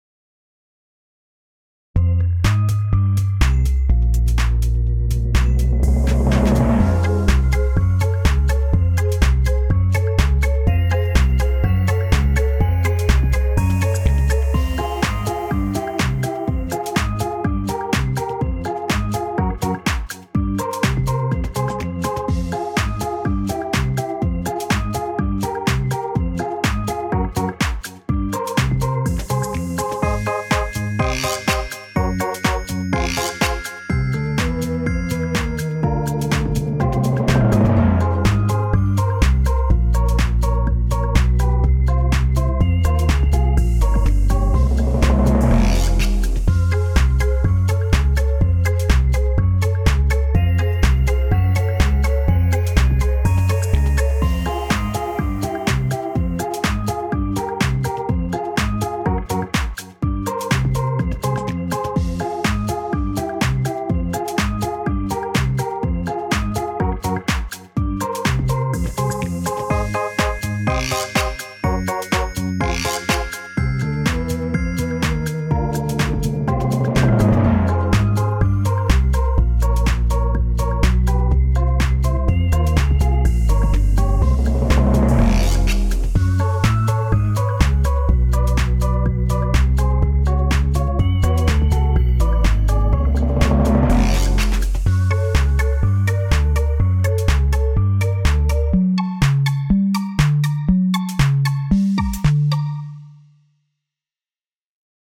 【Instrumental（DTM）】 mp3 DL ♪